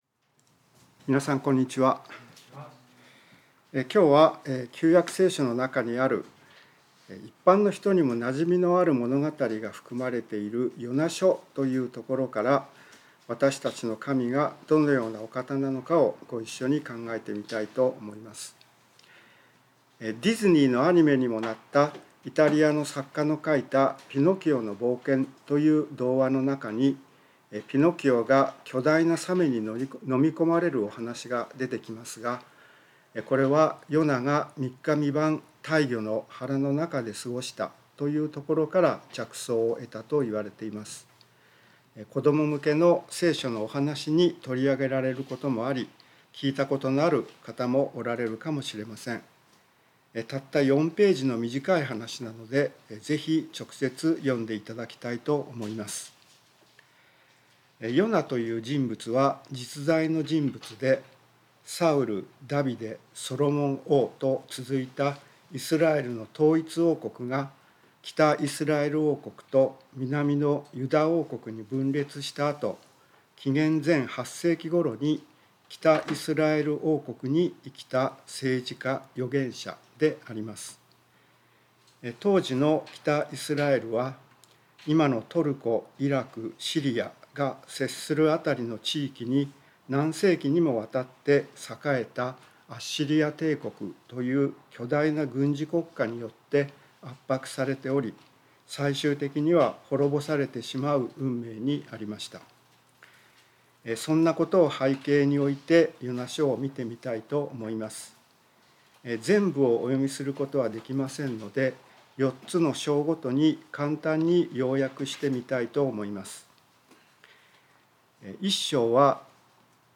聖書メッセージ No.286